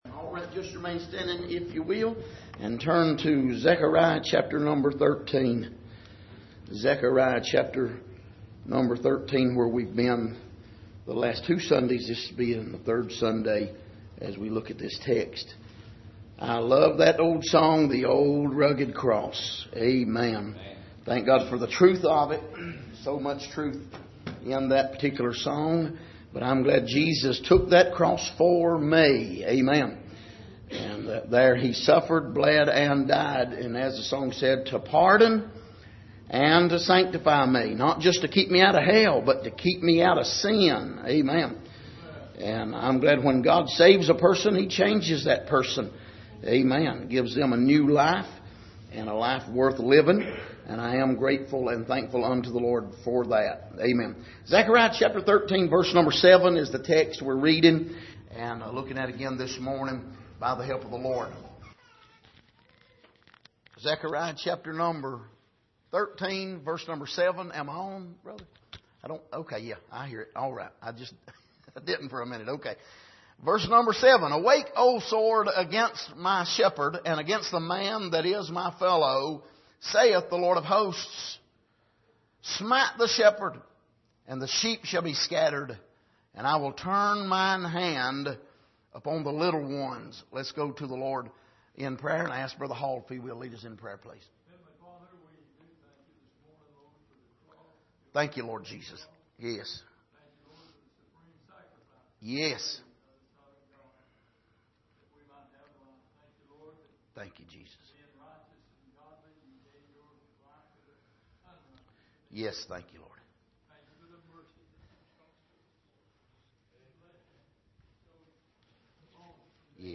Passage: Zechariah 13:7 Service: Sunday Morning